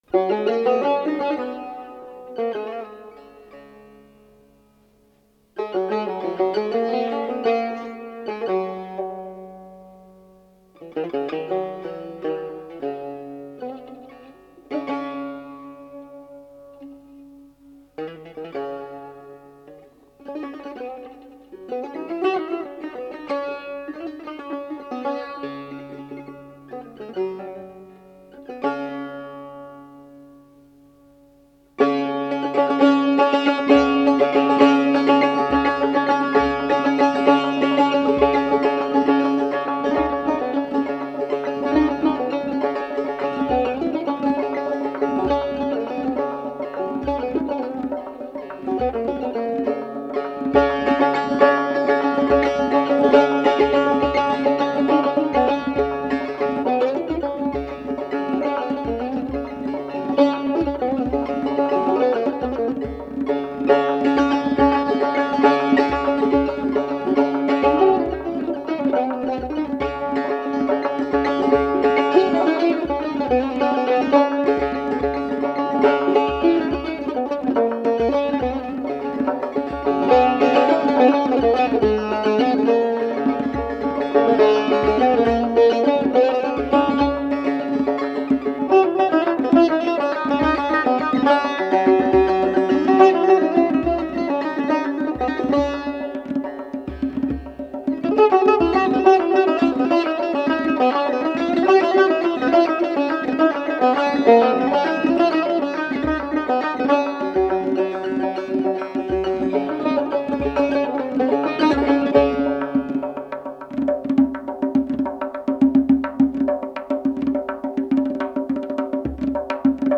Donavazie Tar va Tonbak